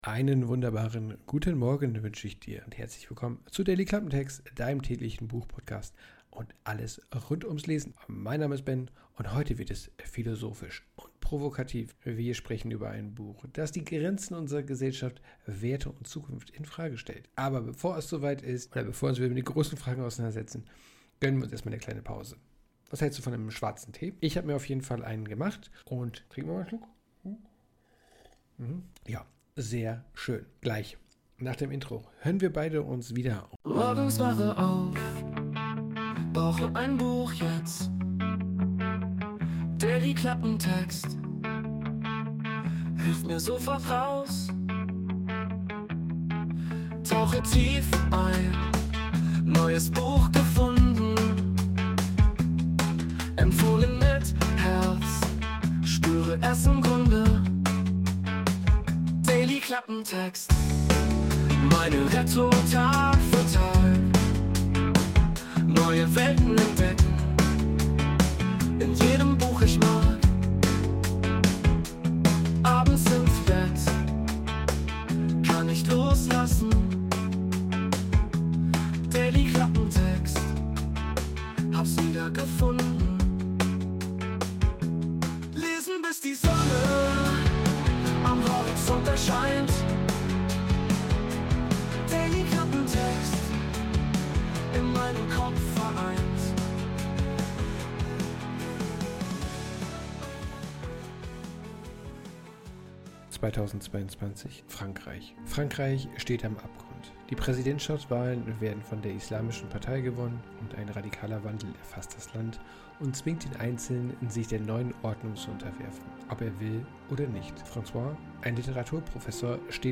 Cinematic Music No Copyright
Intromusik: Wurde mit der KI Suno erstellt. Text: Eigener Text